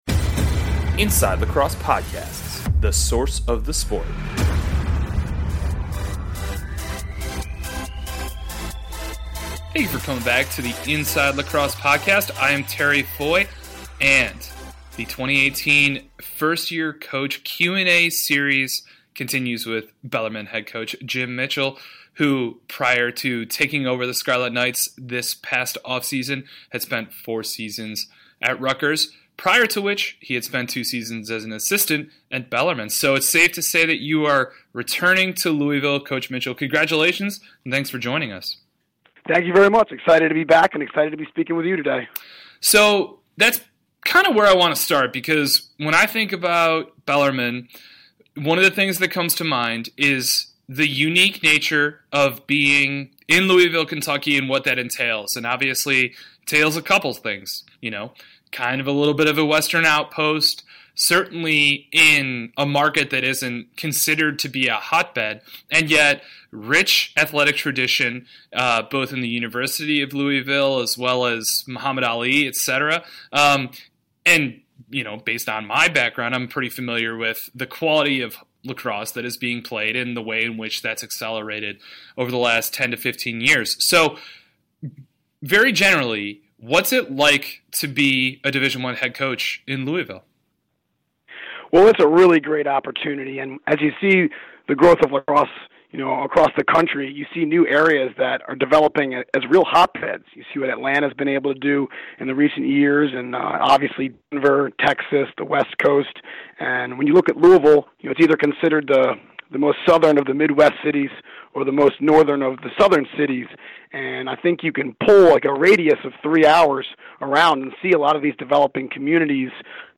10/29 New Coach Interview